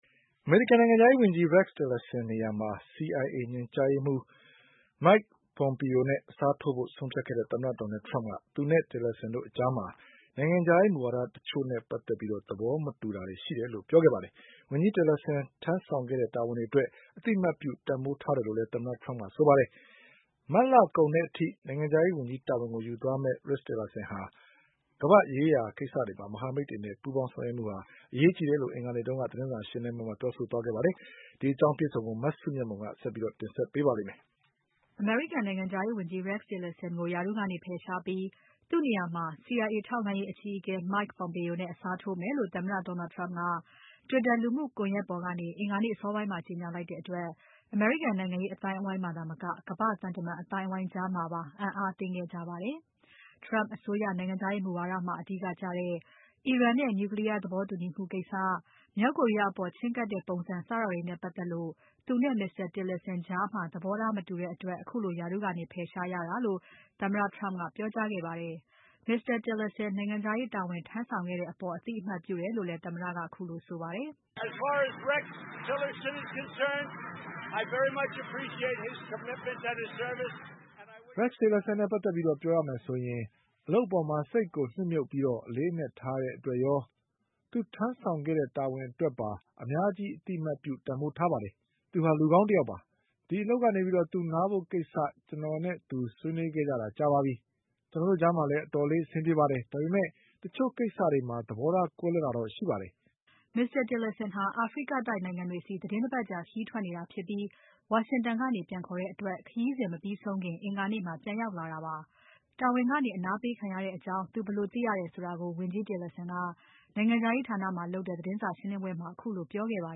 ရာထူးကနေ ဖယ်ရှားခြင်းခံရသူ ဝန်ကြီး Tillerson သတင်းစာရှင်းလင်းပွဲ